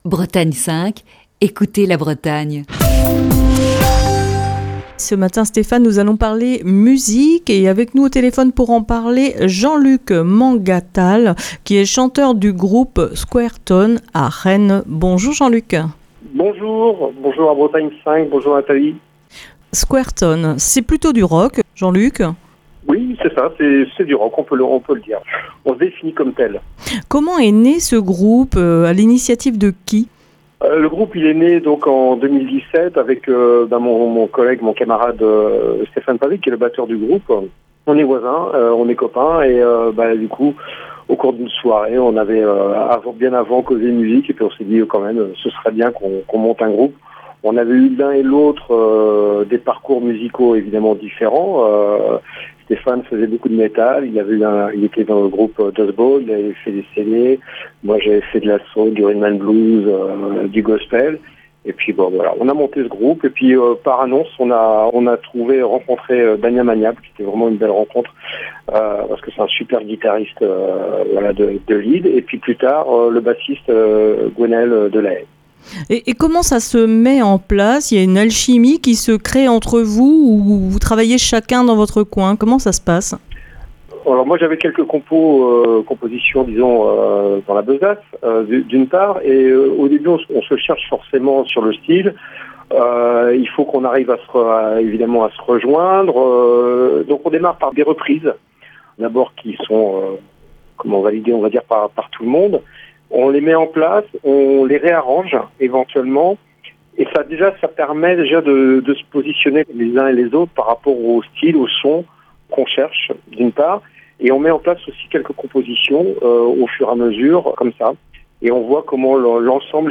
Ce mardi dans le coup de fil du matin